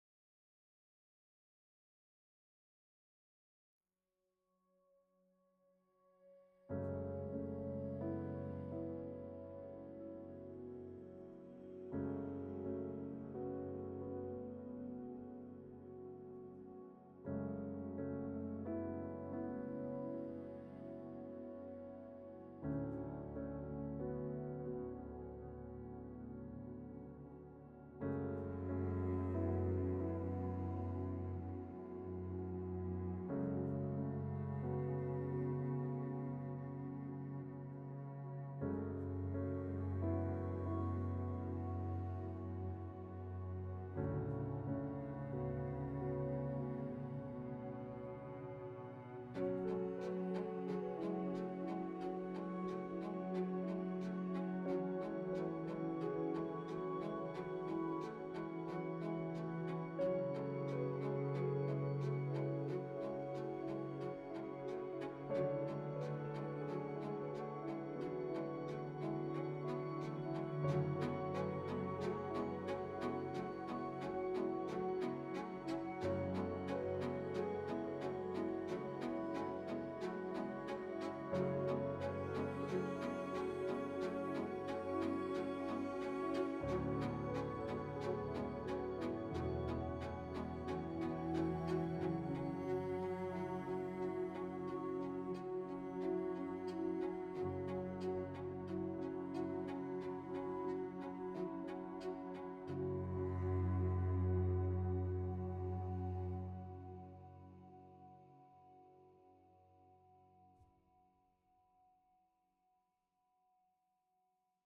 the score for the documentary